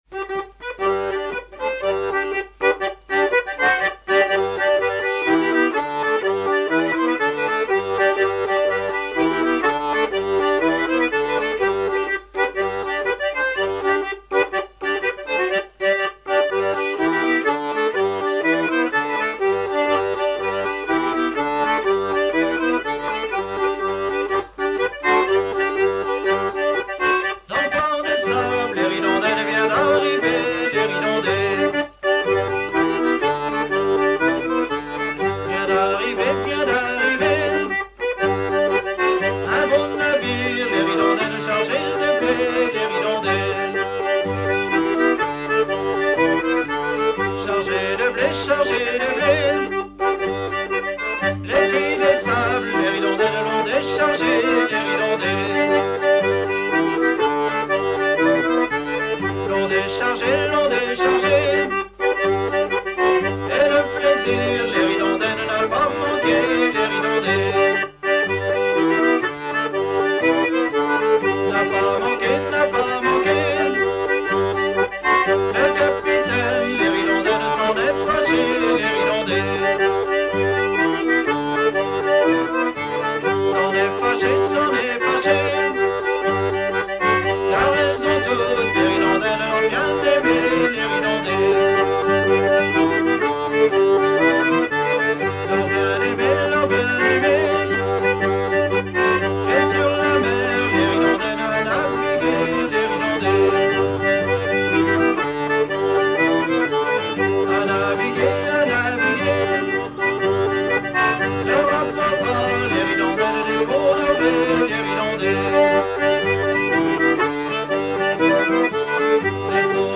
Ronde à trois pas
danse : ronde à trois pas
Dix danses menées pour des atelirs d'apprentissage
Pièce musicale inédite